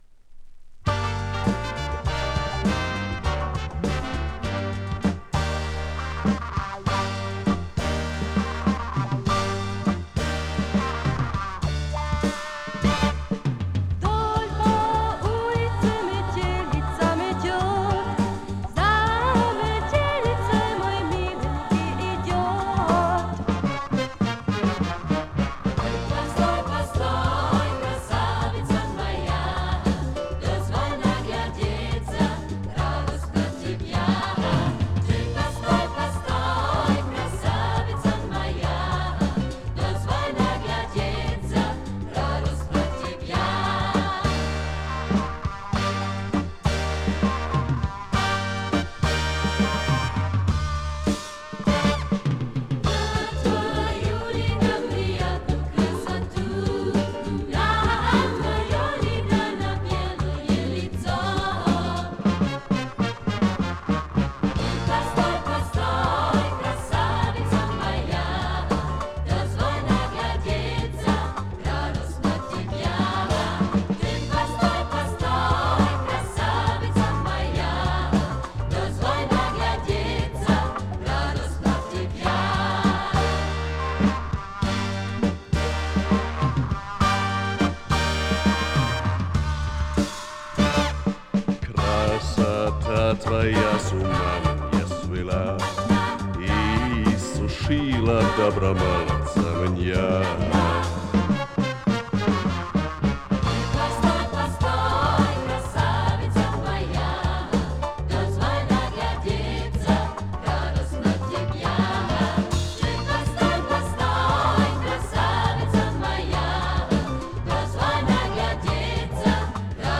Русская народная